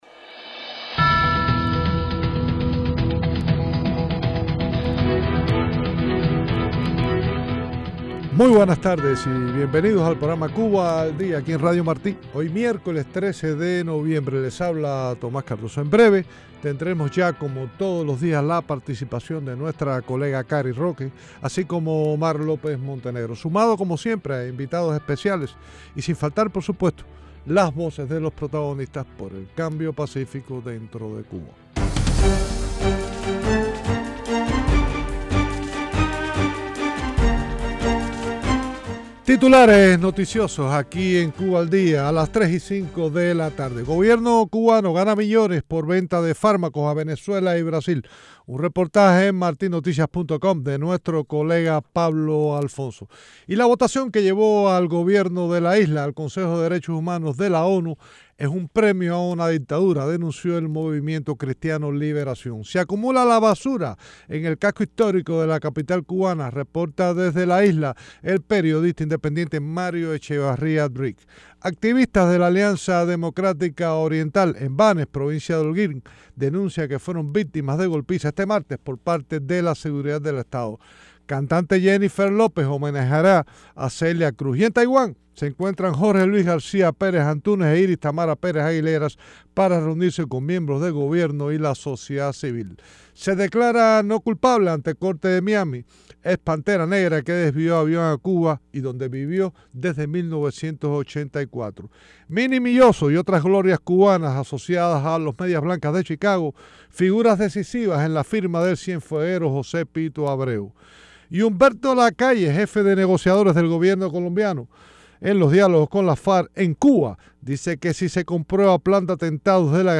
Entrevista Con Paola Holguín.